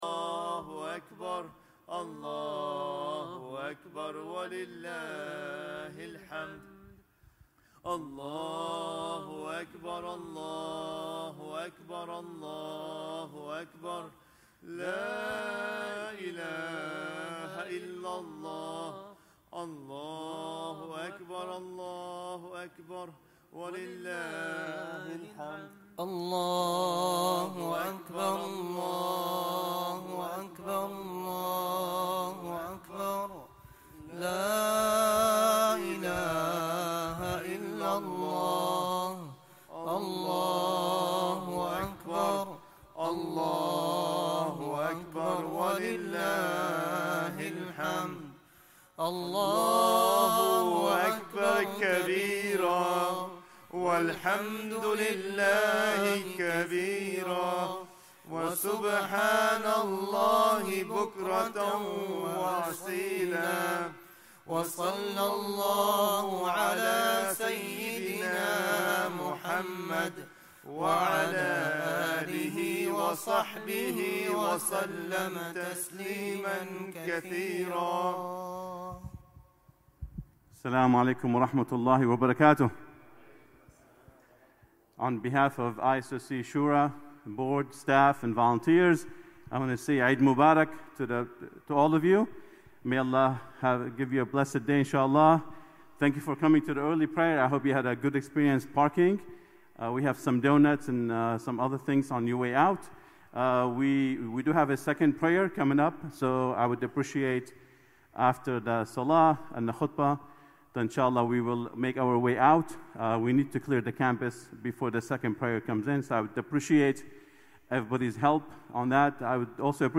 Eid-ul-Adha 2021 Prayer and Khutbah
Eid-ul-Adha Khutbah